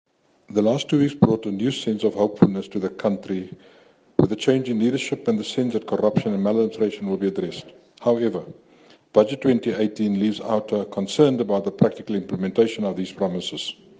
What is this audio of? SOUNDBITES: